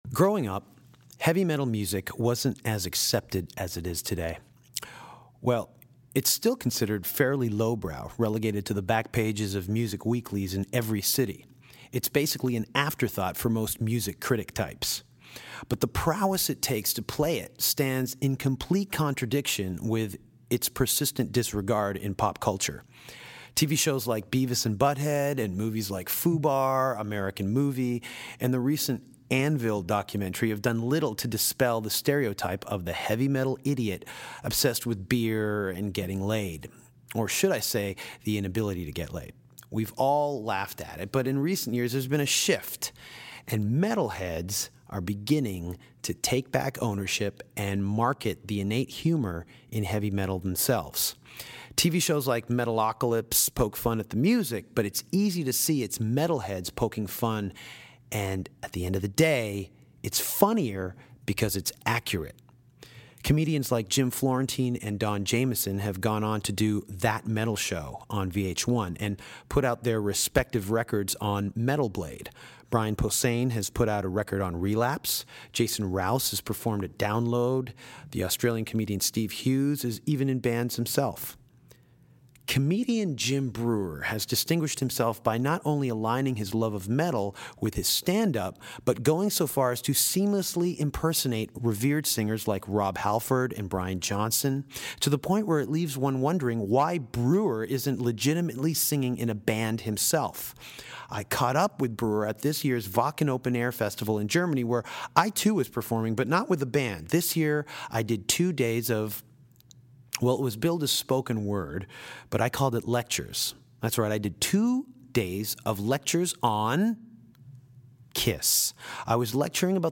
Danko caught up with Jim Breuer this past August at The Wacken Open Air Festival in Germany and talked about The Scorpions, That Metal Show, Rob Halford and fronting a metal band himself.